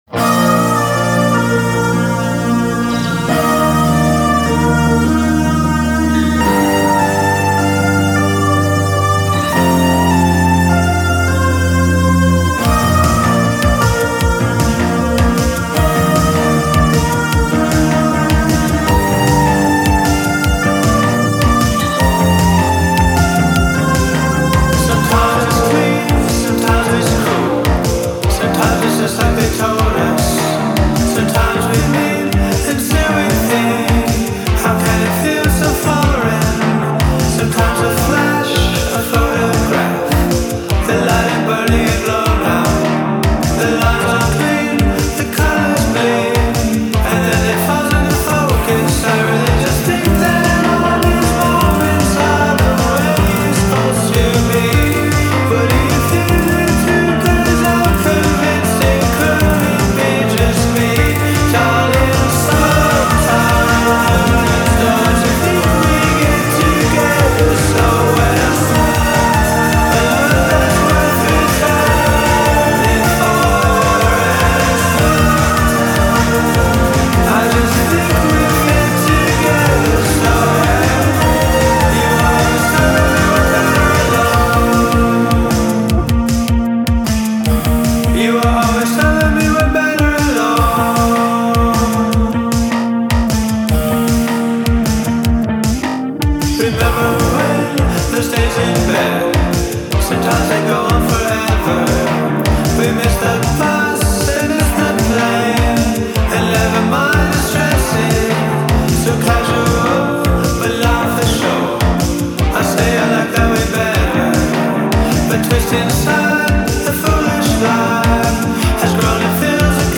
lo-fi-ish, neo-new-wave-ish